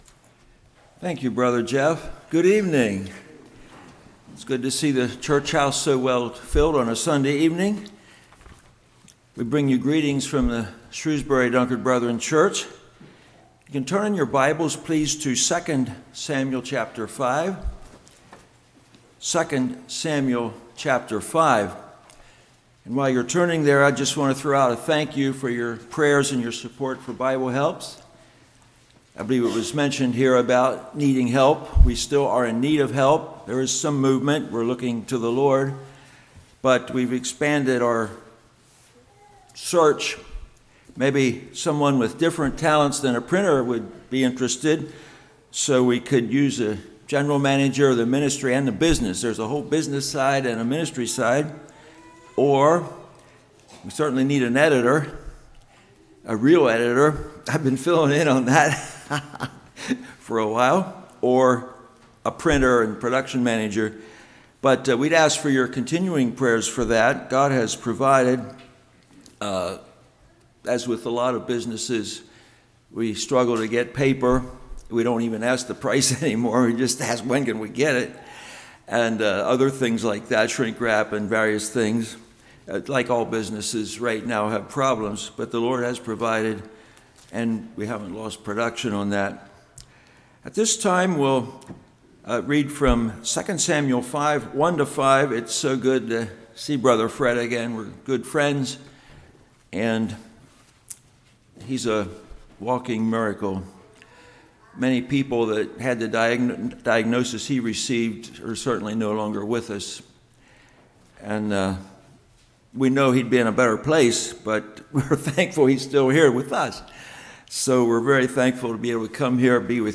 2 Samuel 5:1-5 Service Type: Revival Anointing/Child of God